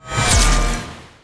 melee-hit-13.mp3